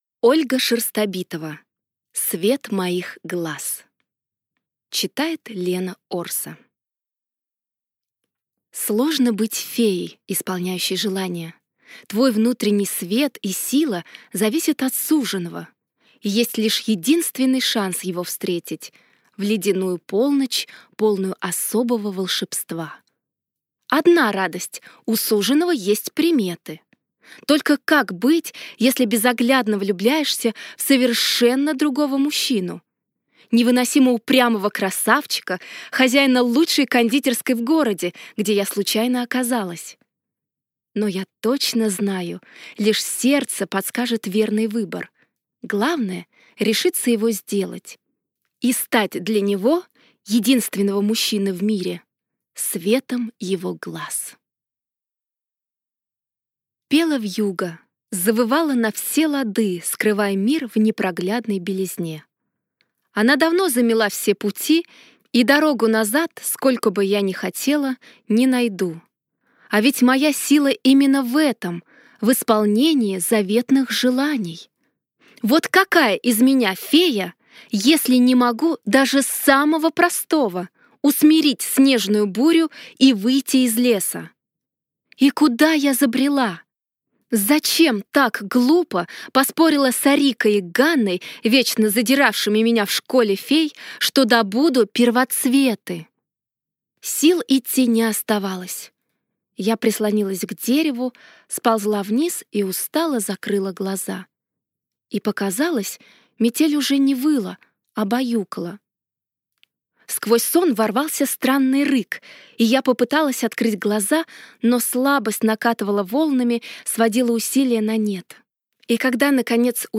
Аудиокнига Свет моих глаз | Библиотека аудиокниг